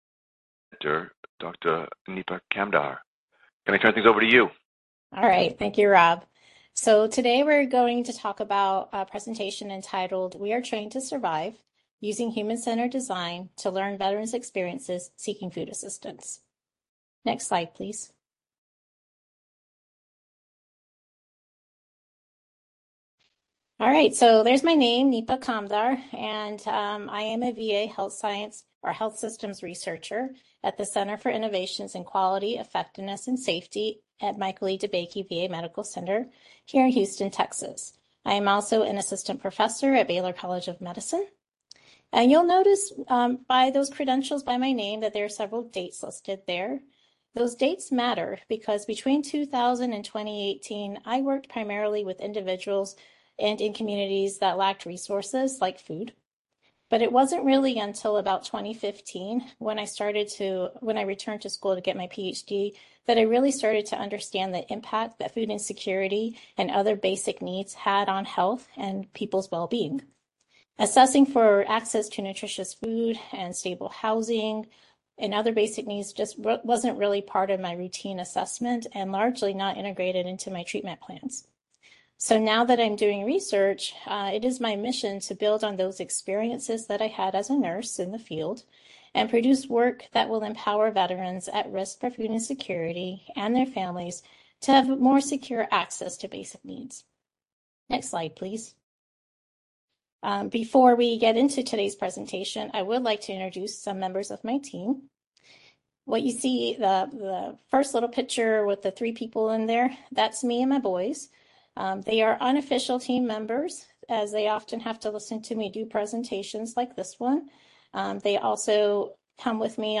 FNP-BC Seminar date